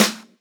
osu-logo-heartbeat.wav